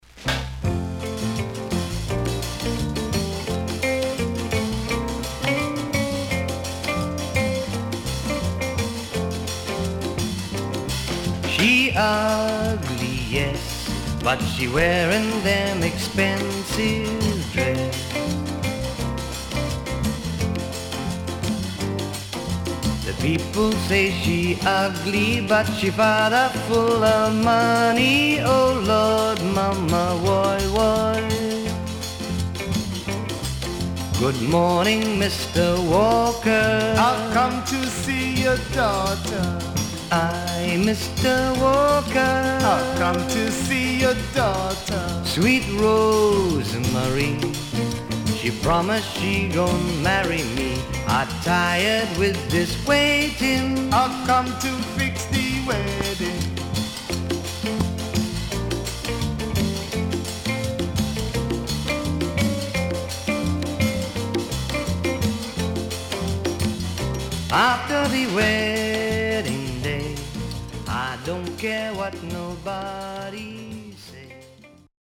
SIDE A:少しノイズあり、曲によってヒスが入りますが良好です。